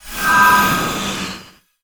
magic_shine_light_spell_03.wav